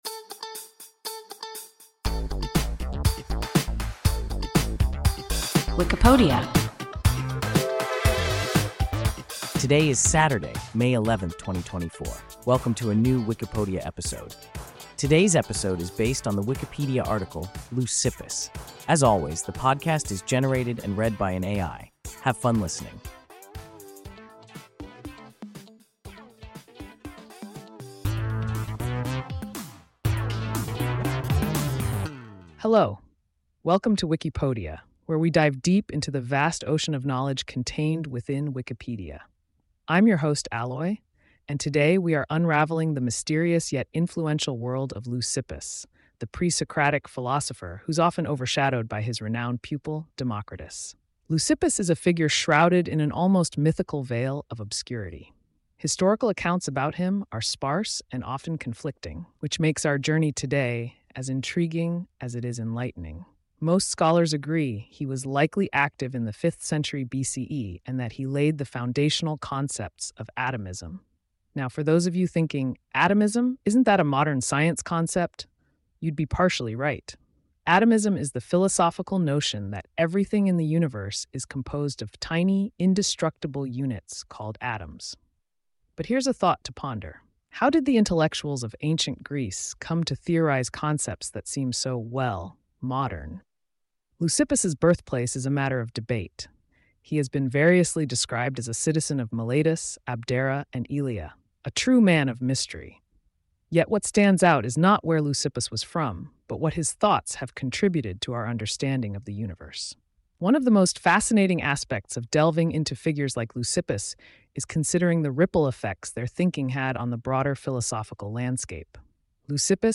Leucippus – WIKIPODIA – ein KI Podcast